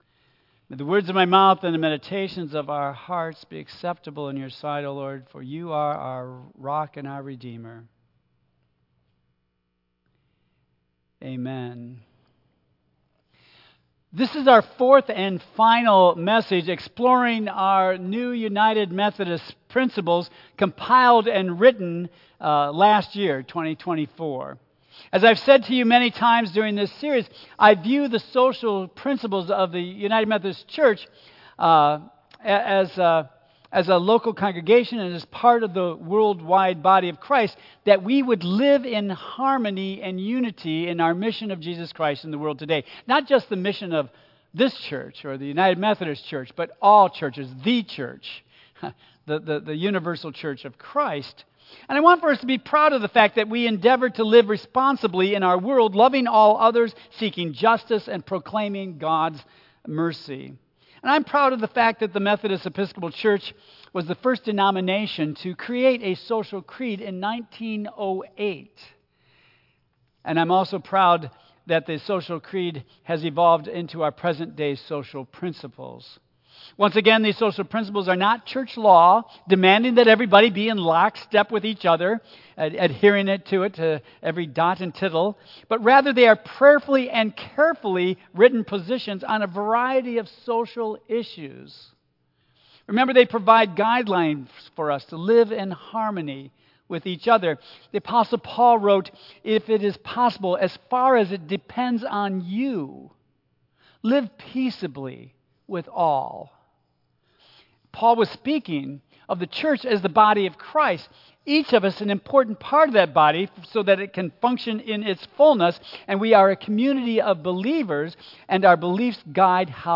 Tagged with Central United Methodist Church , Michigan , Sermon , Waterford , Worship